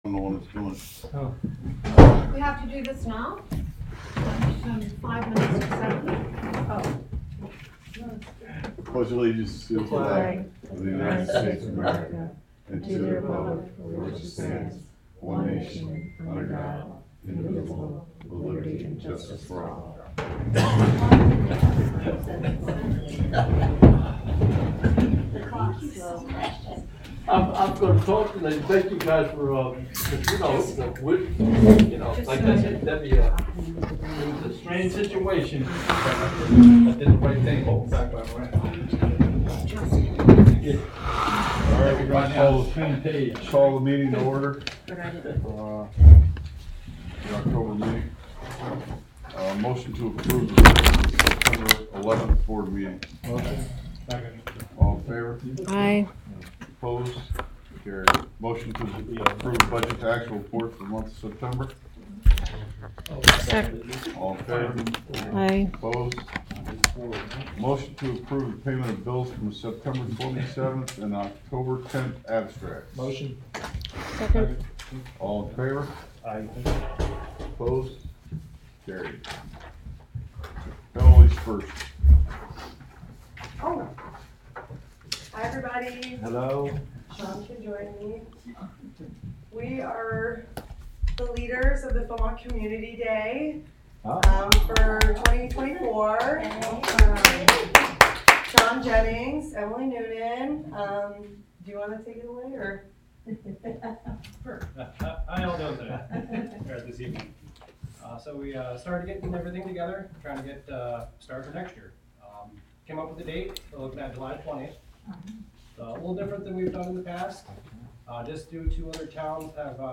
Live from the Village of Philmont: Village Board Meeting (Audio)